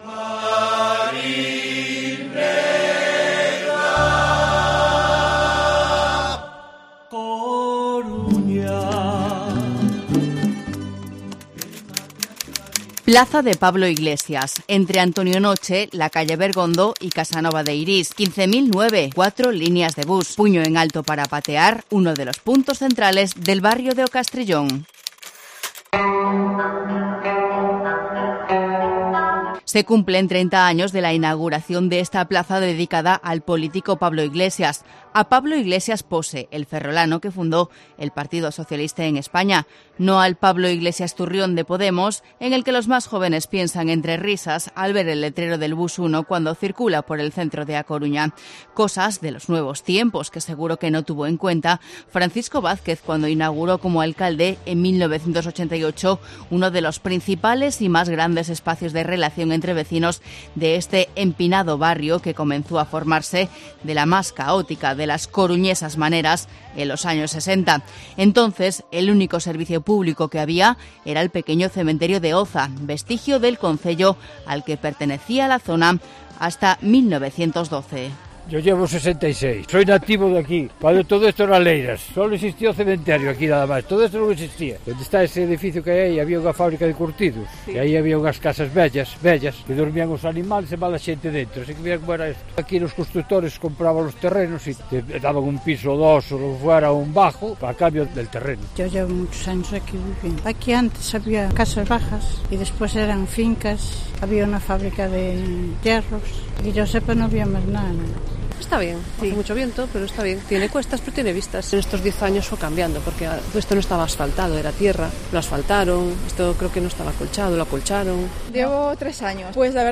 Hemos escuchado las impresiones y las demandas de los vecinos .